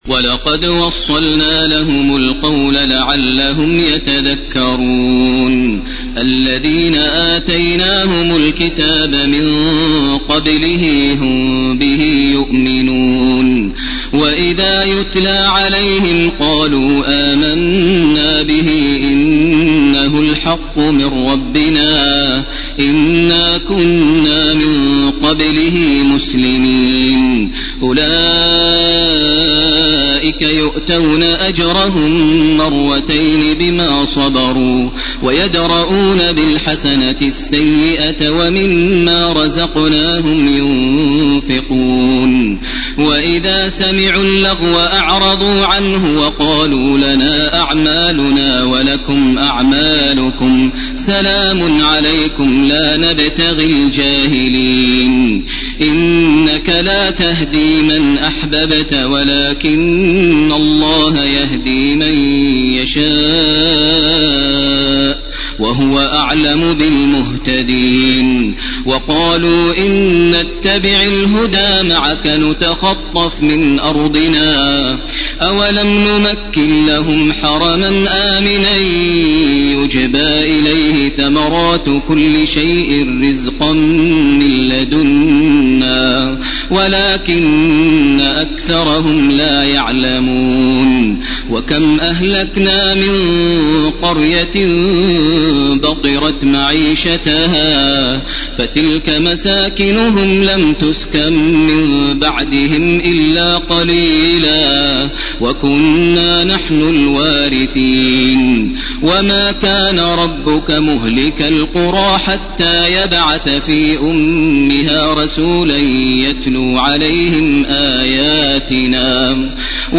ليلة 20 رمضان لعام 1431 هـ من الآية 51 من سورة القصص وحتى الآية 45 من سورة العنكبوت. > تراويح ١٤٣١ > التراويح - تلاوات ماهر المعيقلي